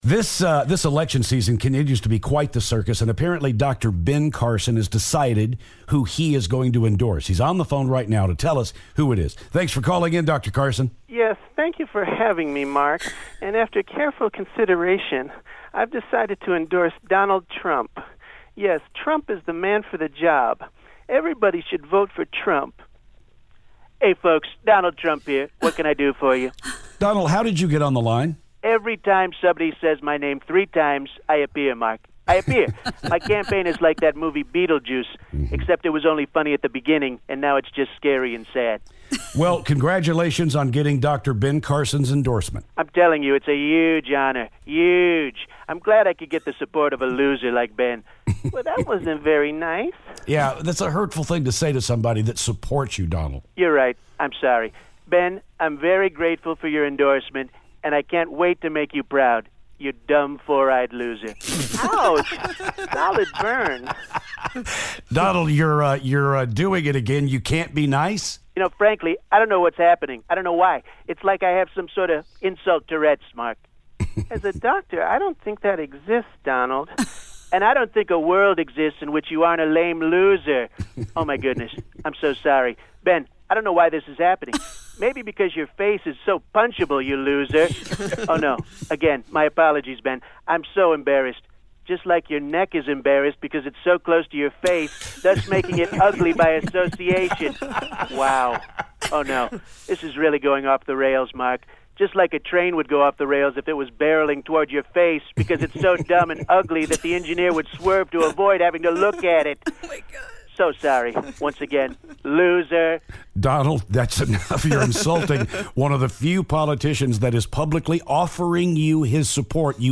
Ben Carson/Donald Trump Phoner
Ben Carson calls to talk about his endorsement of Donald Trump.